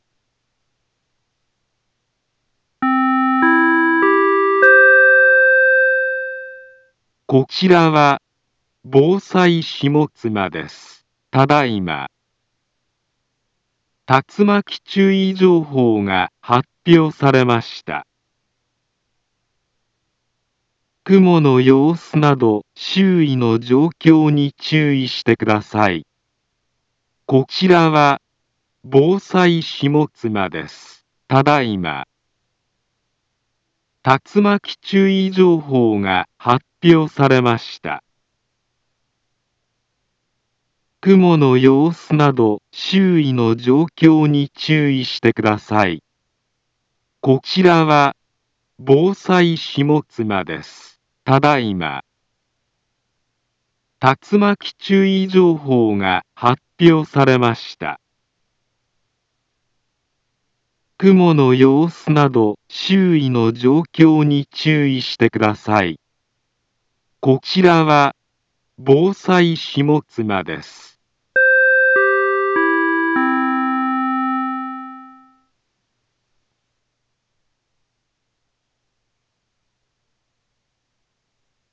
Back Home Ｊアラート情報 音声放送 再生 災害情報 カテゴリ：J-ALERT 登録日時：2023-05-22 20:00:02 インフォメーション：茨城県南部は、竜巻などの激しい突風が発生しやすい気象状況になっています。